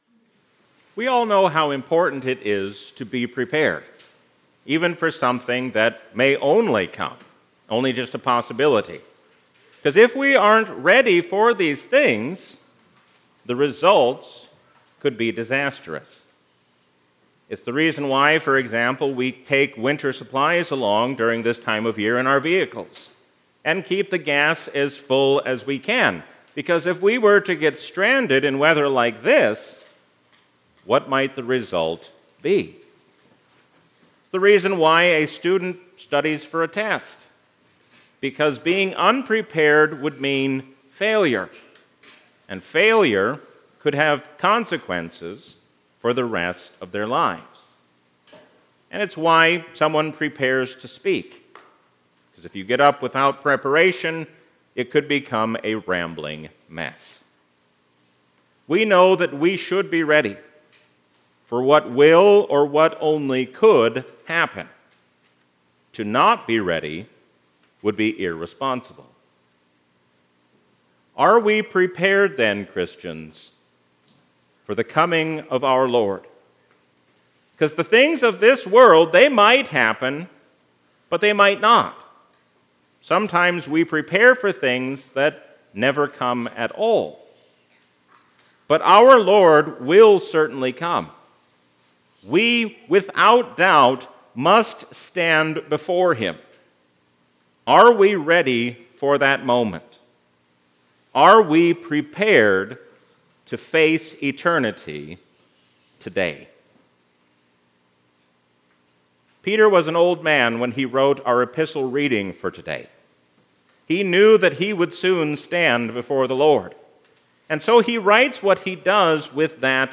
A sermon from the season "Epiphany 2025." Stephen shows us what it means to be like Jesus even in a difficult hour.